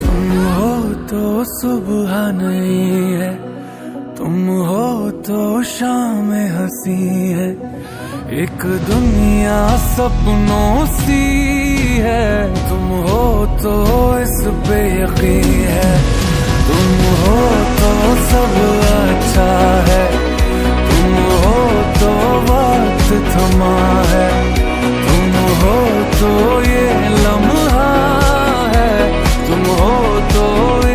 soothing and heart-touching ringtone